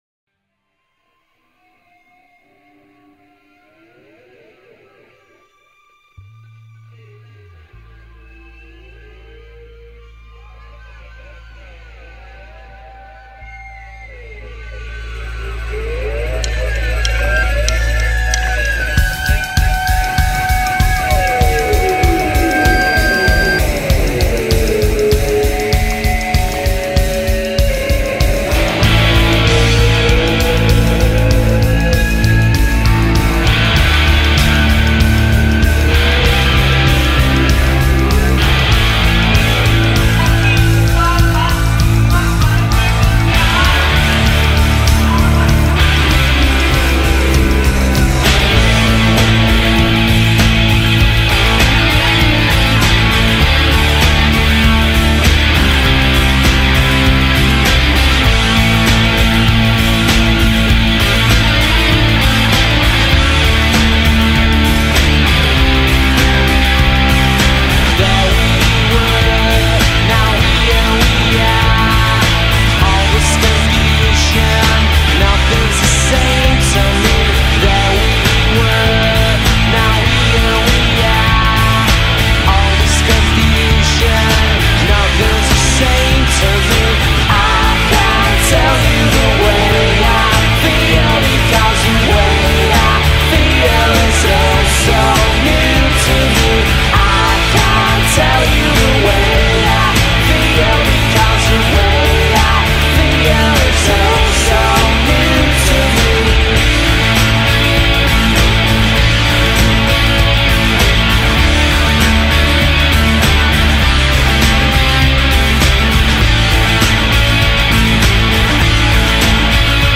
Britpop, Rock